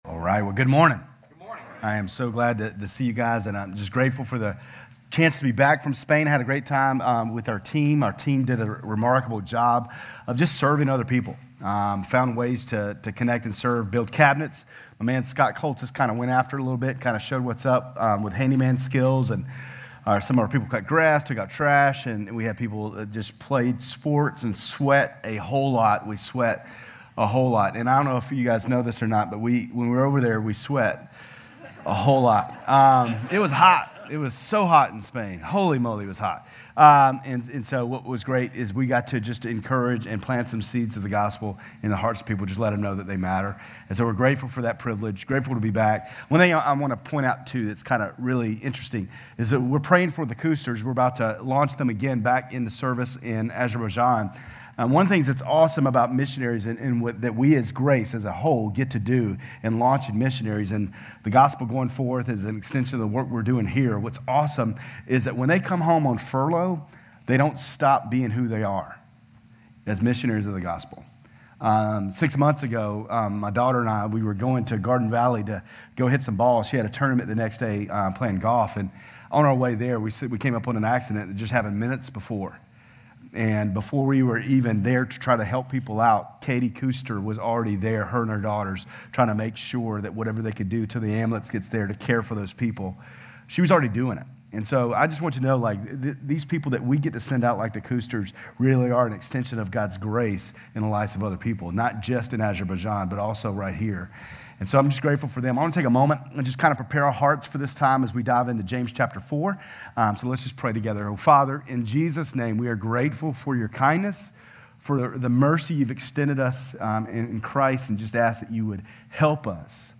GCC-Lindale-July-23-Sermon.mp3